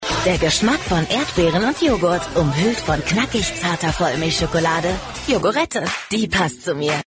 Werbung TV Yogurette